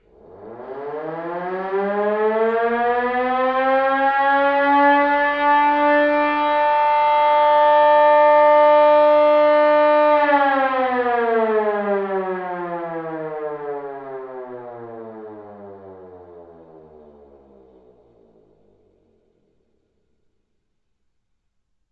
警报器低
描述：警笛声投下。
Tag: 空袭 警报器